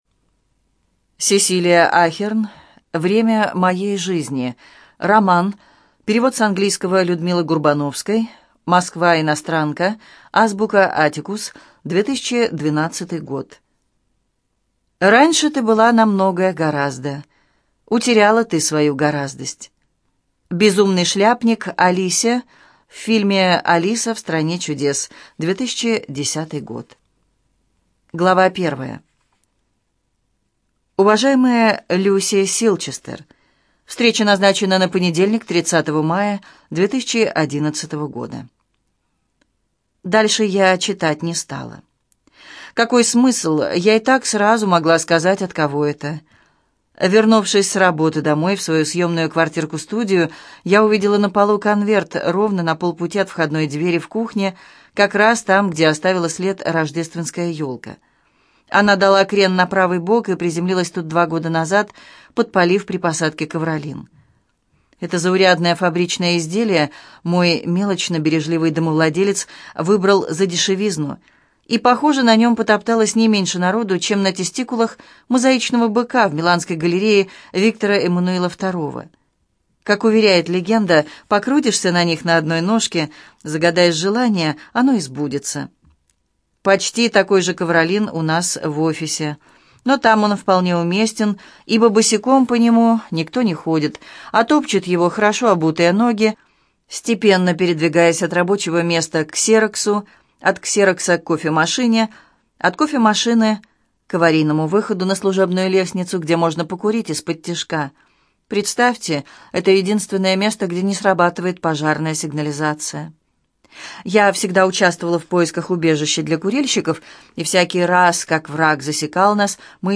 ЖанрЛюбовная проза
Студия звукозаписиЛогосвос